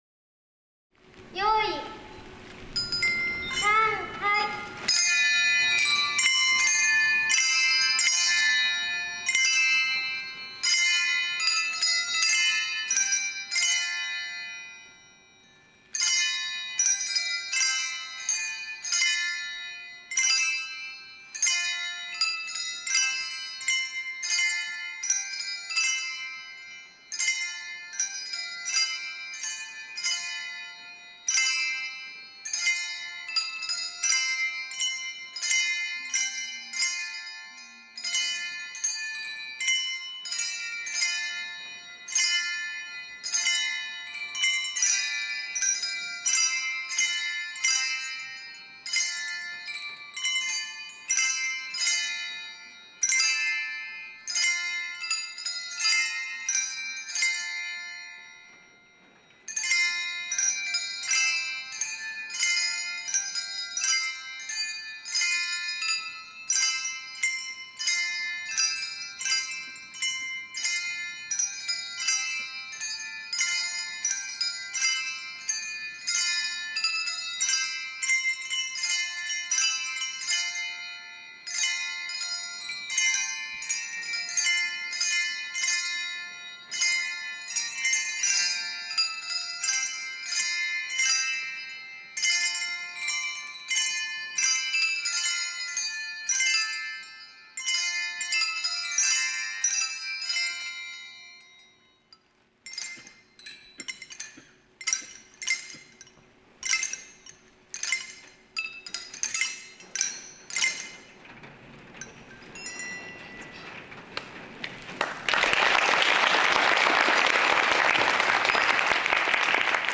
【♪】福賀小３・4年生ハンドベル「もみじ」｜山口県阿武町役場